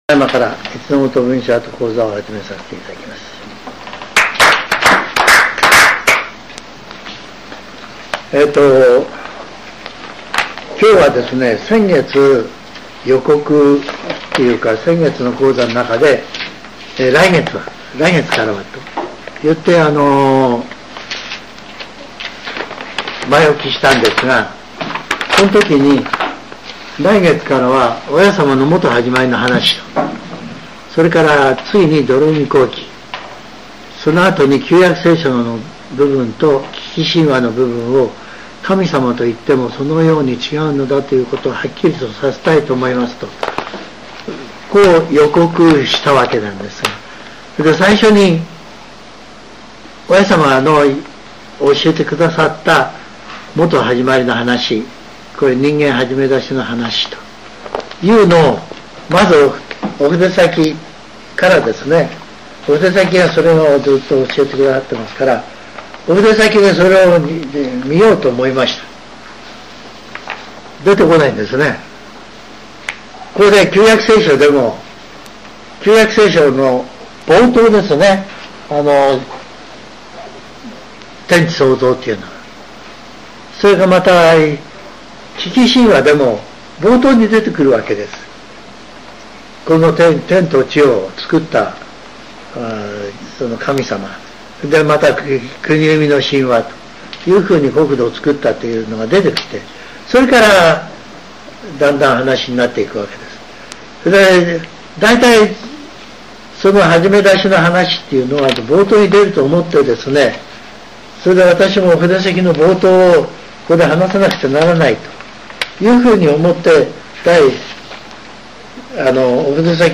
全70曲中58曲目 ジャンル: Speech